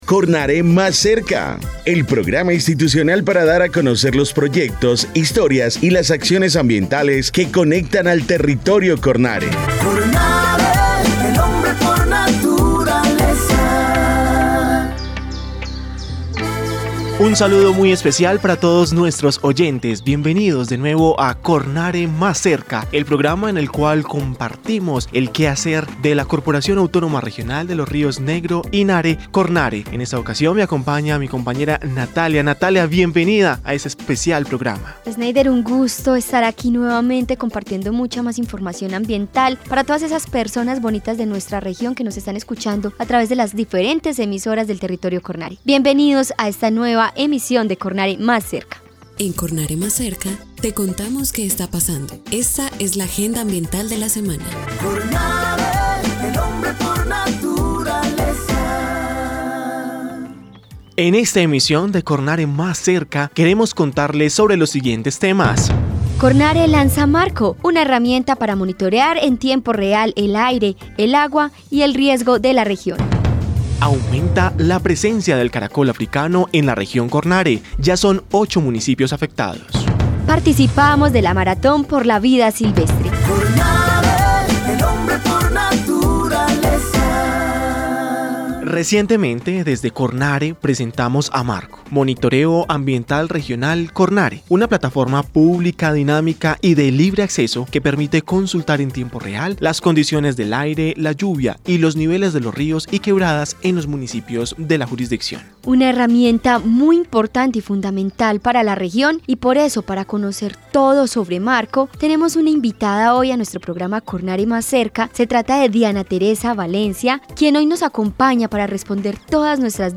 Programa de radio 2025